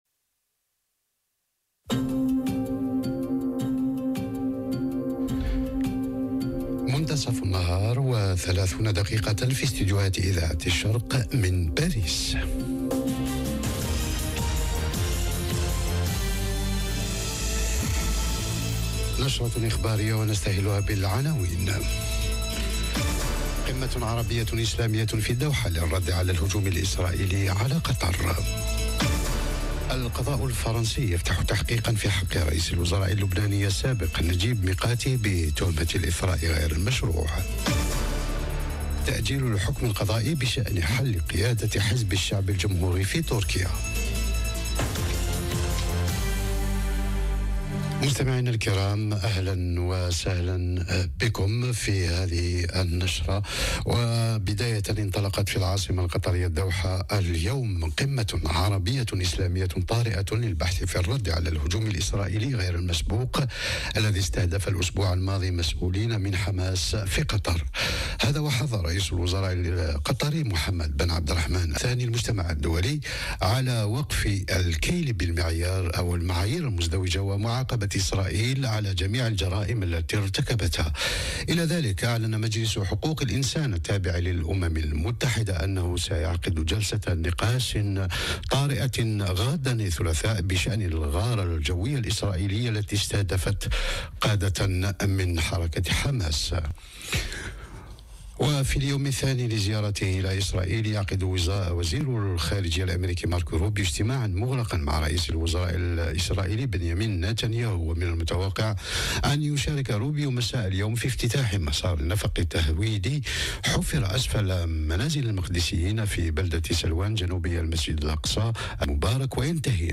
نشرة أخبار الظهيرة: قمة الدوحة وتطورات قضائية في فرنسا وتركيا تتصدر المشهد العربي والدولي - Radio ORIENT، إذاعة الشرق من باريس